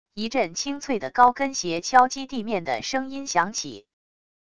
一阵清脆的高跟鞋敲击地面的声音响起wav音频